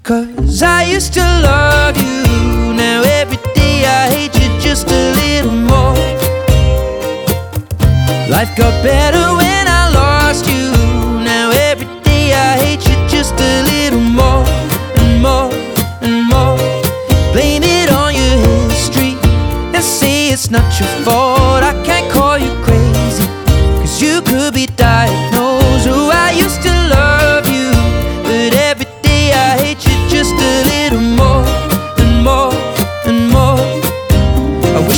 Pop Singer Songwriter
Жанр: Поп музыка